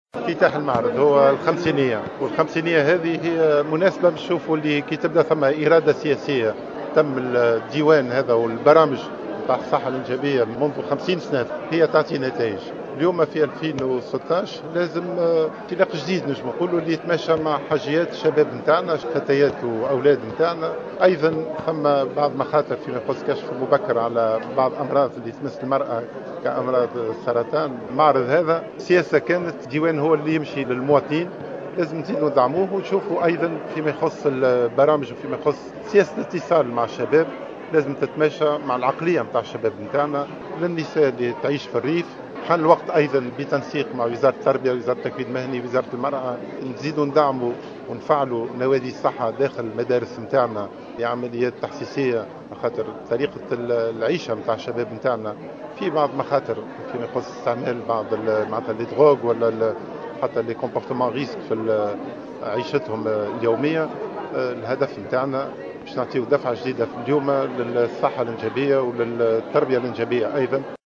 أكد وزير الصحة اليوم الإثنين 16 ماي 2016 على هامش افتتاحه معرضا وثائقيا احتفالا بمرور 50 سنة على انطلاق البرنامج الوطني للتنظيم العائلي والصحة الانجابية أن الإرادة السياسية تتجه للعناية خاصة للاهتمام بفئة الشباب من أجل مساعدته على تنظيم نمط عيشه والوقاية من الأمراض التي يمكن أن تهدده.